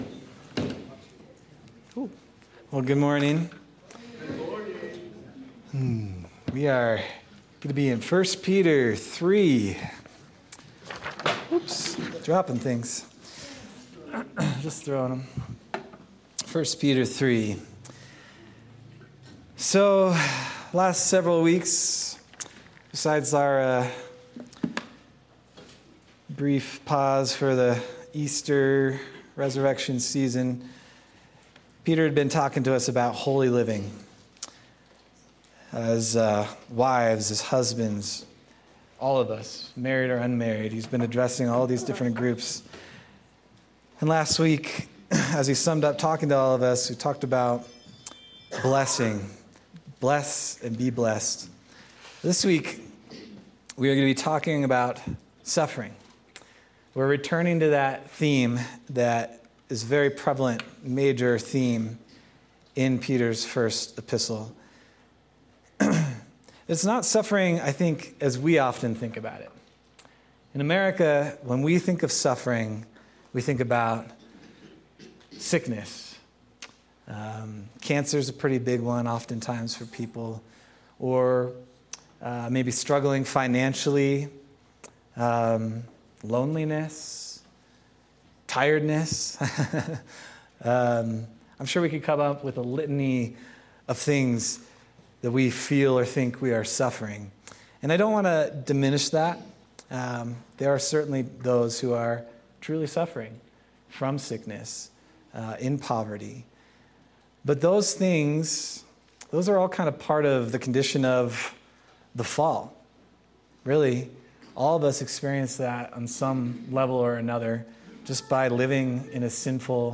May 18th, 2025 Sermon